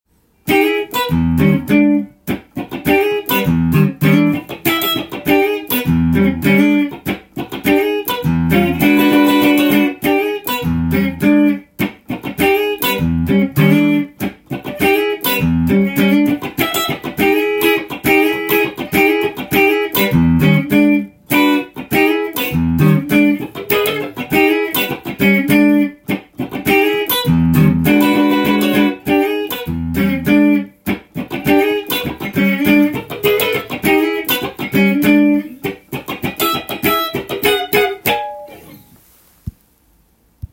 譜面通り弾いてみました
かなり渋いフレーズが満載なので思わずグッとこみ上げるものがあります。
コードはE7ワンコードで繰り広げられていますが
６弦の開放弦を使っている点です。